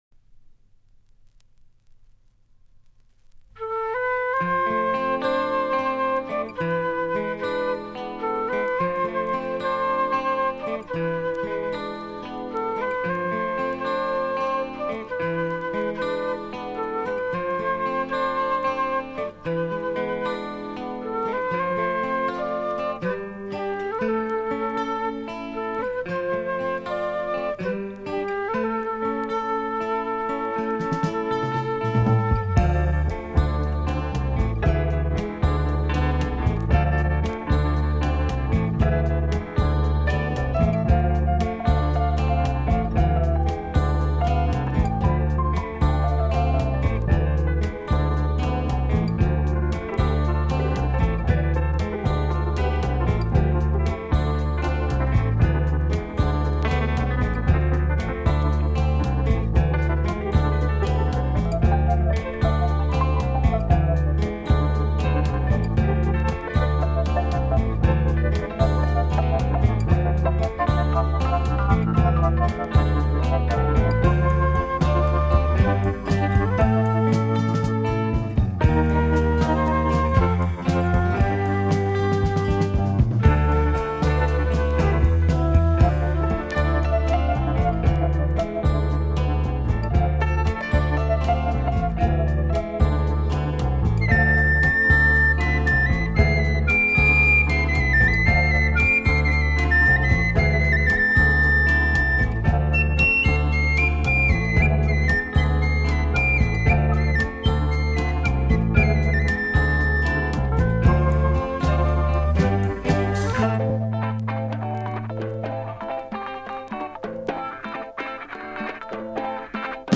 Jazz influenced Progressive rock approach